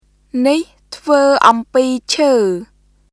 [ニヒ・トゥヴー・オンピー・チュー　nih tʰvə̀ː ʔɔmpiː cʰə̀ː]